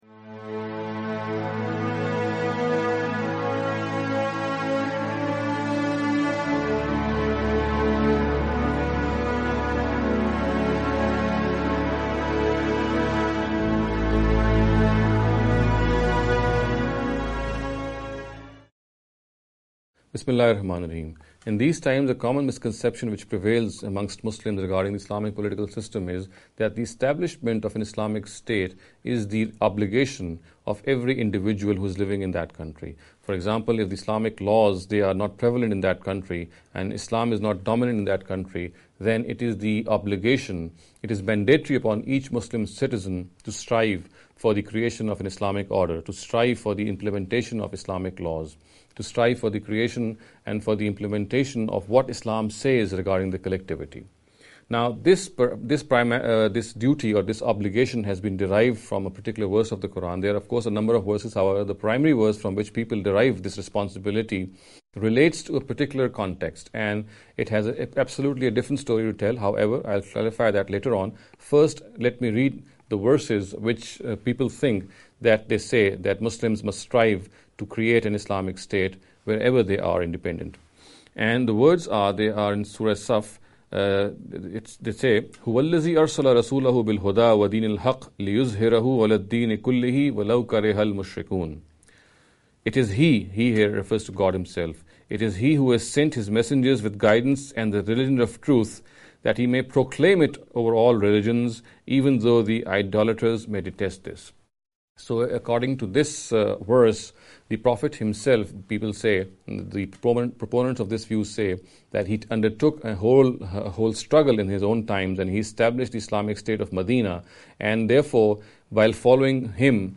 This lecture series will deal with some misconception regarding the Concept of the Hereafter.